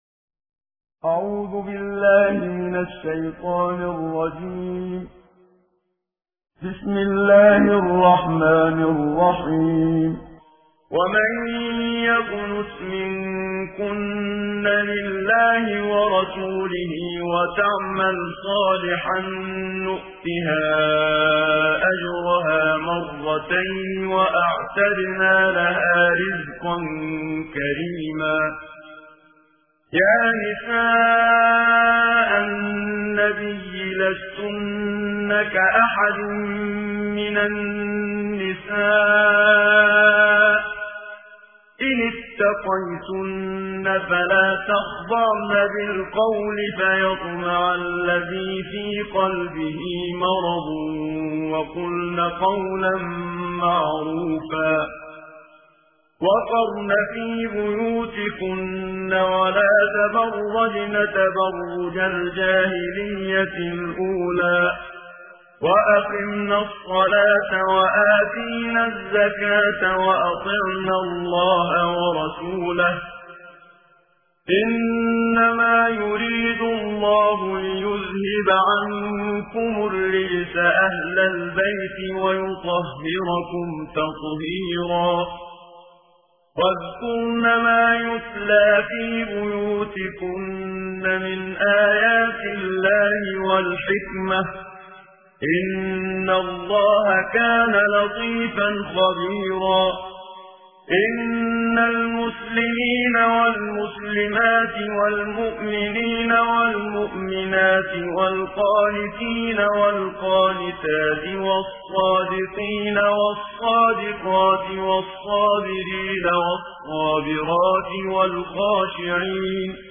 صوت تلاوت ترتیل جزء بیست‌ودوم قرآن کریم